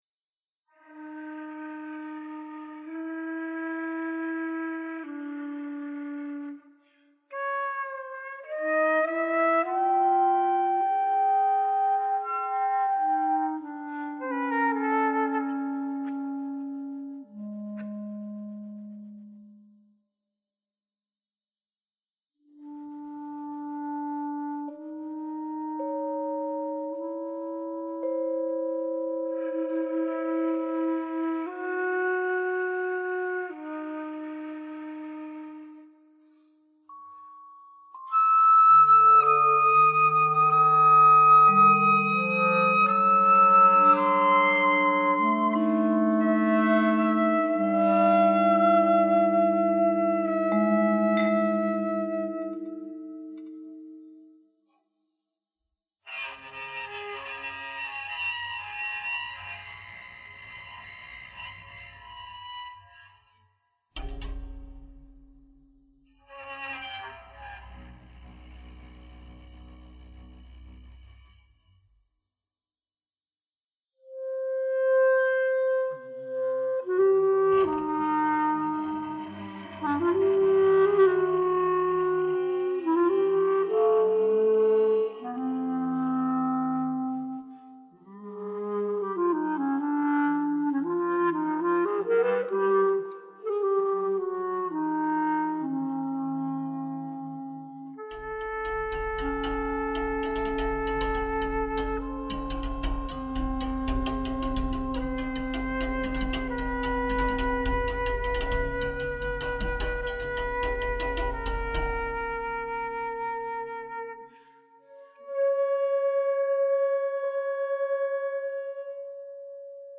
for flute, clarinet and percussion